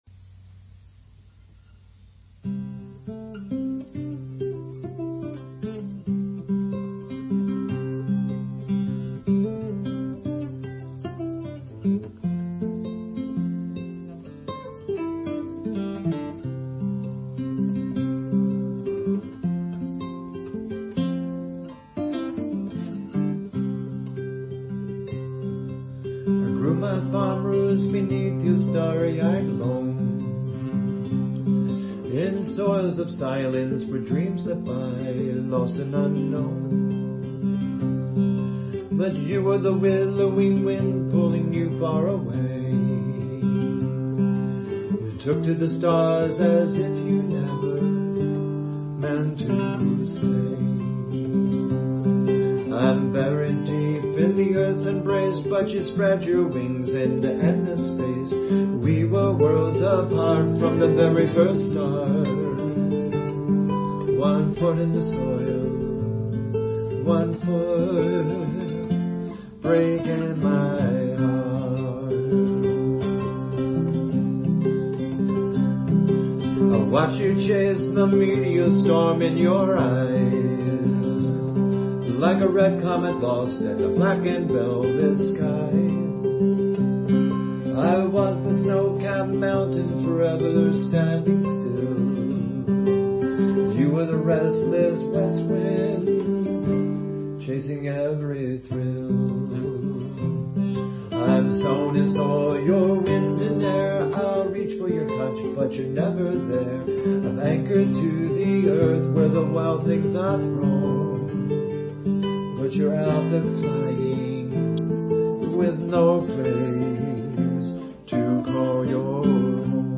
The poignant ache of realization touches deeply and is enhanced by your plaintive vocals and emotive guitar work.